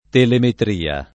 telemetria [ telemetr & a ]